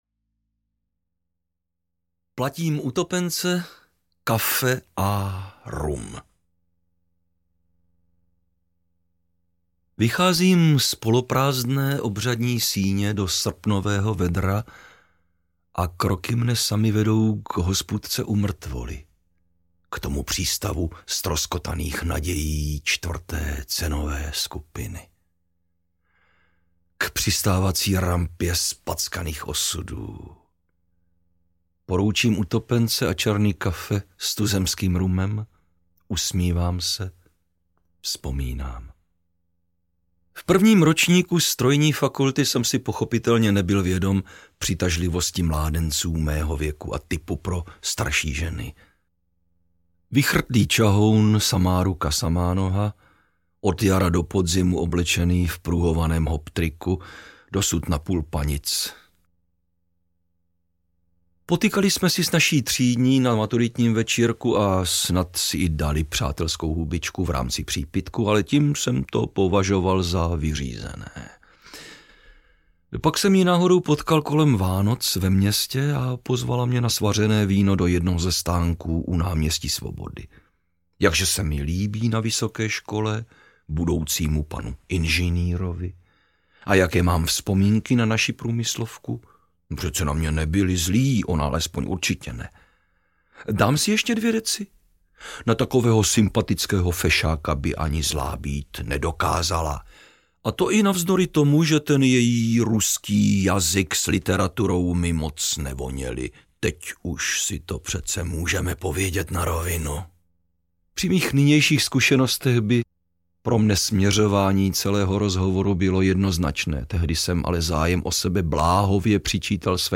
Na sviňu svět (komplet) audiokniha
Ukázka z knihy